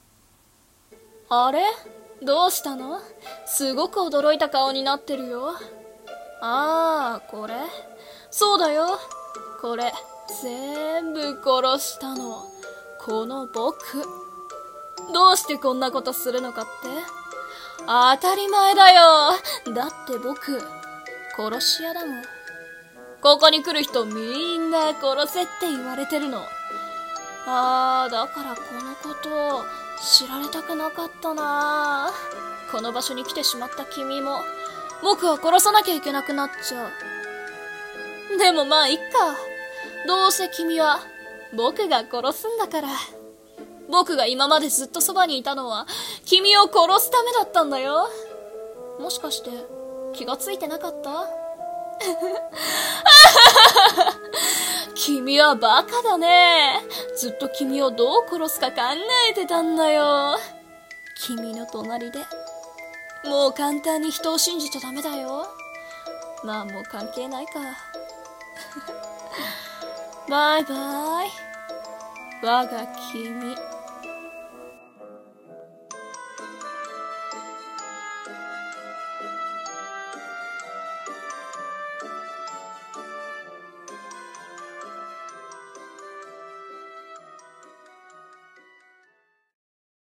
【声劇】 殺し屋のアナタ